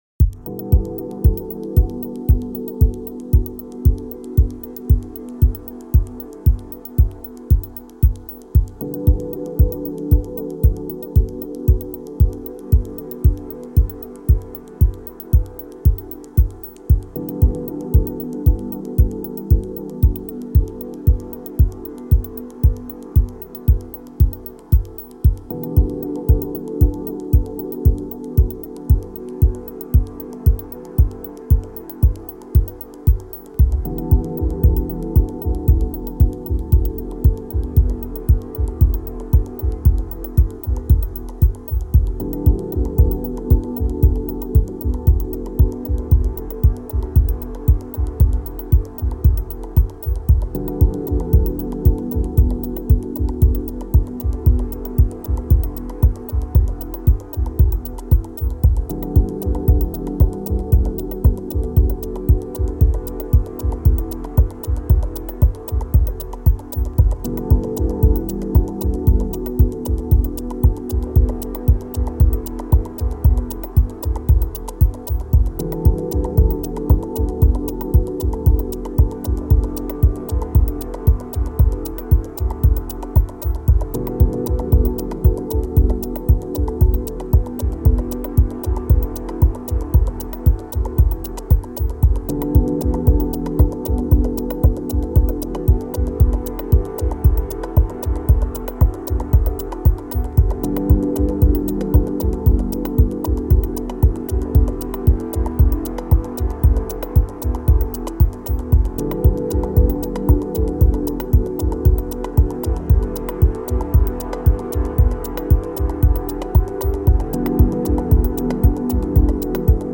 Genre: Dub Techno.